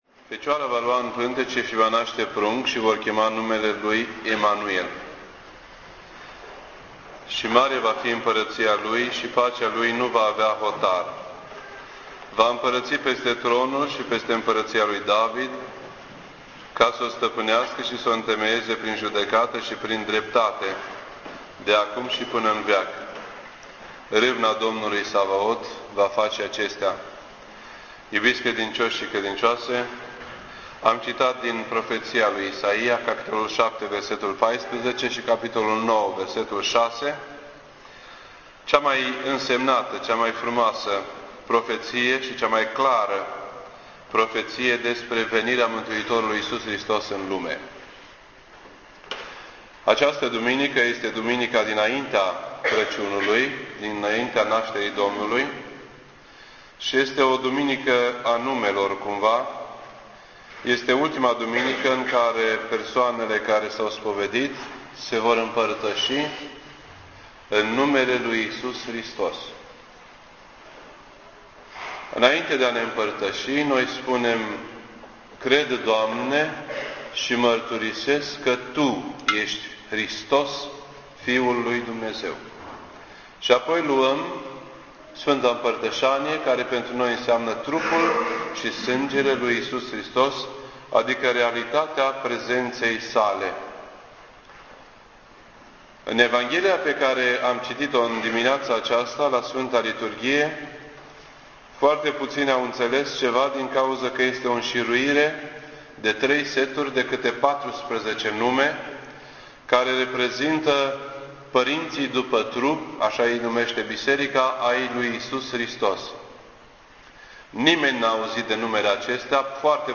This entry was posted on Sunday, December 21st, 2008 at 7:19 PM and is filed under Predici ortodoxe in format audio.